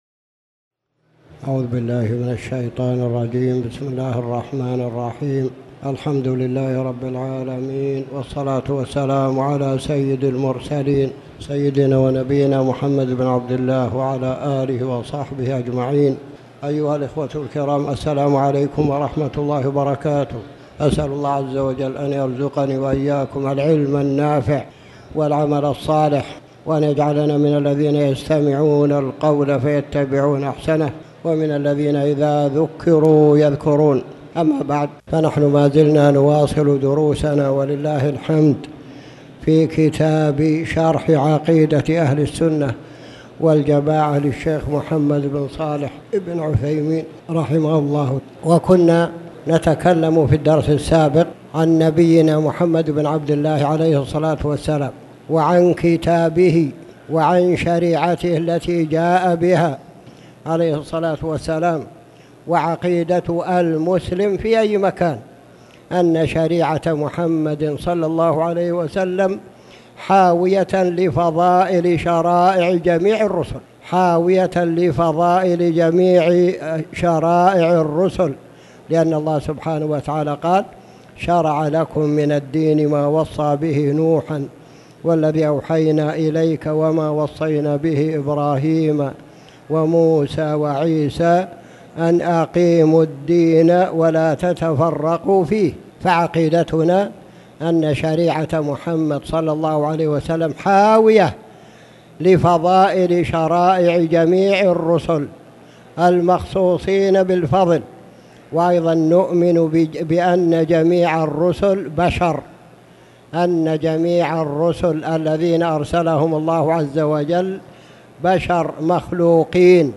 تاريخ النشر ٢٦ صفر ١٤٣٩ هـ المكان: المسجد الحرام الشيخ